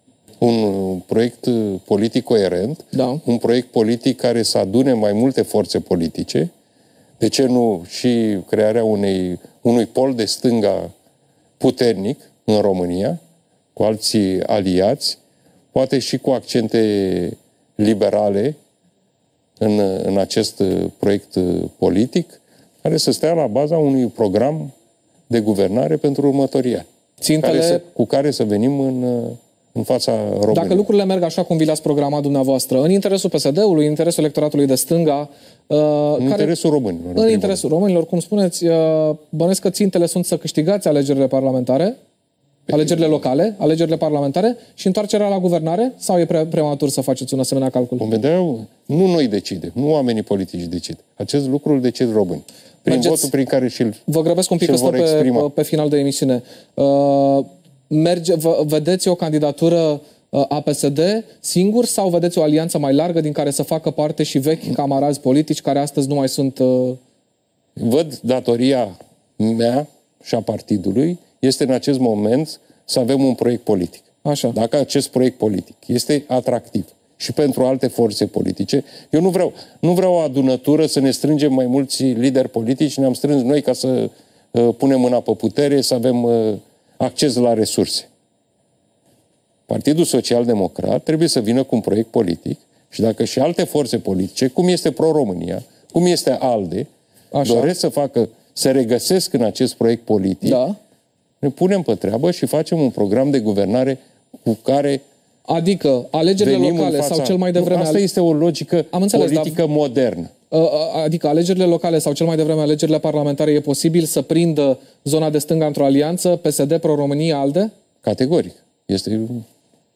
”Marea majoritate a colegilor au susţinut că trebuie organizat un congres pentru a avea o conducere clar aleasă, care îşi va asuma acest ciclu electoral, atât locale, cât şi parlamentare (…) Consider că trebuie făcut cât mai repede un congres în PSD, imediat după terminarea stării de alertă”, a mai declarat Marcal Ciolacu, luni seara, la televiziunea publică, reafirmând că va candida în competiția internă.